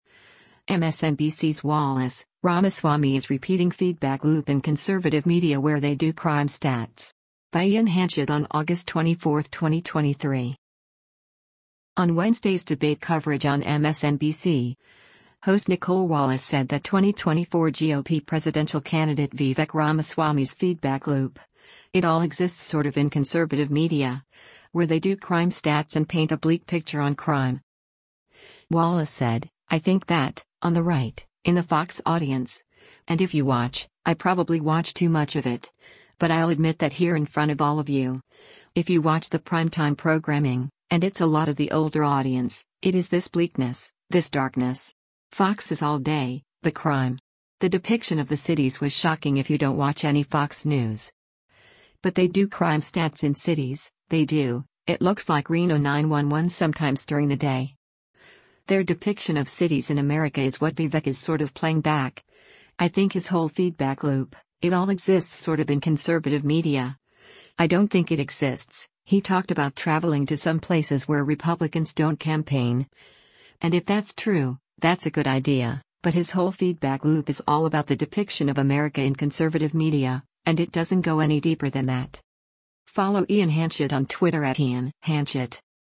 On Wednesday’s debate coverage on MSNBC, host Nicolle Wallace said that 2024 GOP presidential candidate Vivek Ramaswamy’s “feedback loop, it all exists sort of in conservative media,” where “they do crime stats” and paint a bleak picture on crime.